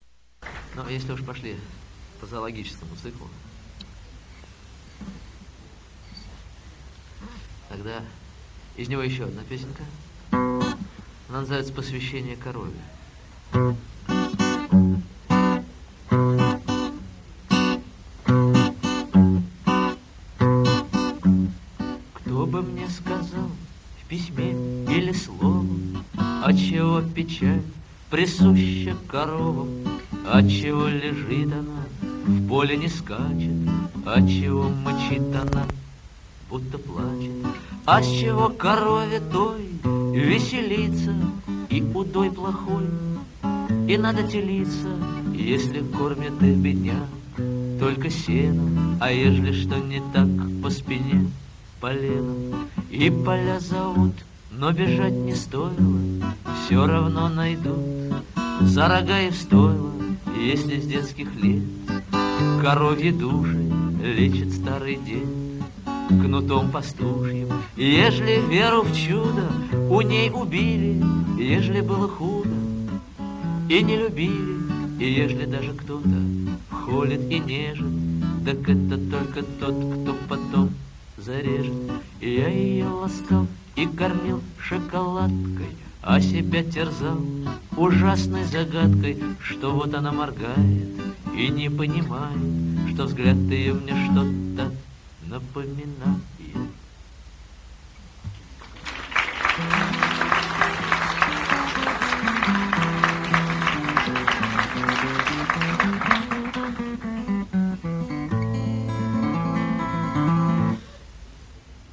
Сольный концерт
в московской школе